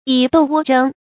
發音讀音
成語拼音 yǐ dòu wō zhēng